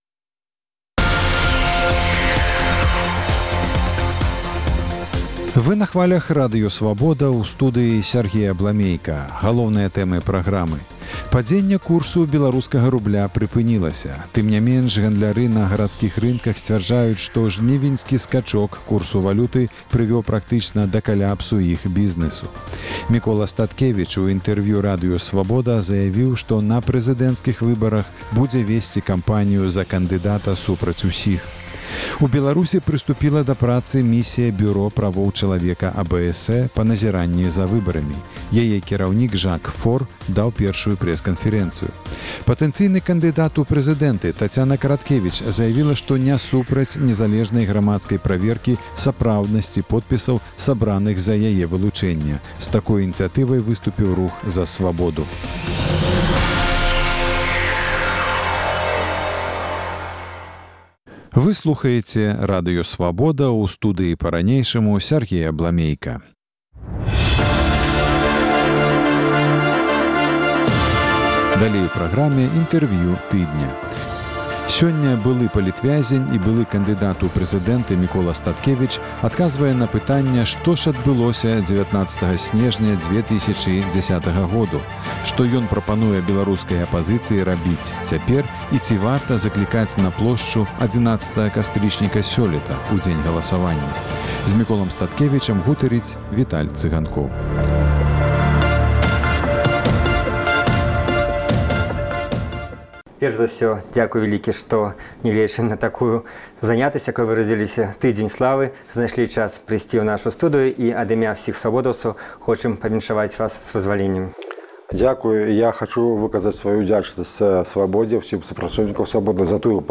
Інтэрвію тыдня: Мікола Статкевіч